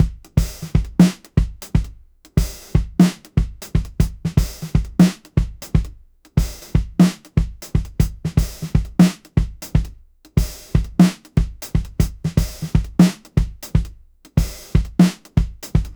audio-to-audio drum-generation drum-loops music-generation
"bpm": 120,
"bars": 8,